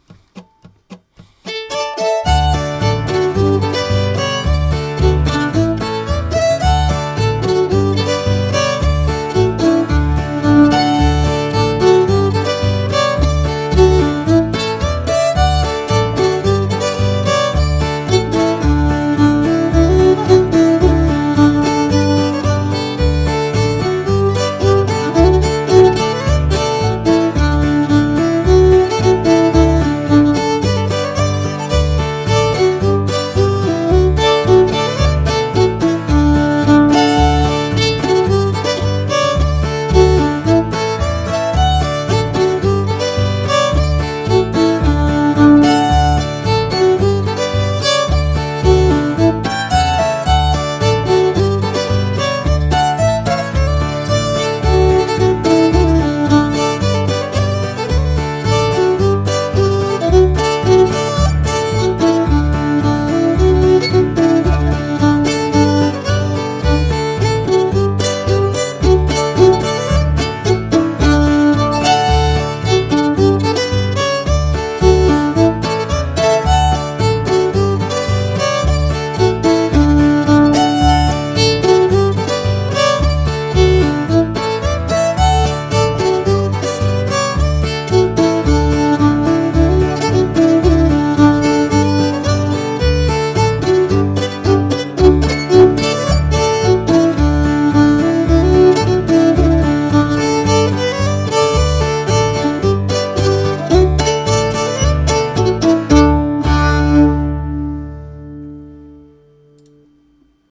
Old Time – Washington Old Time Fiddlers Association